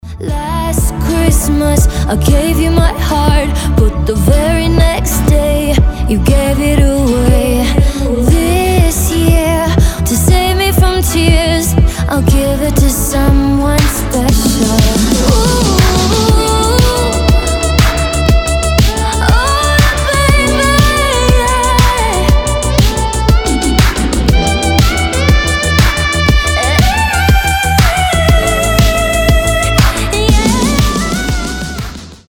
• Качество: 320, Stereo
женский вокал
Synth Pop
праздничные
рождественские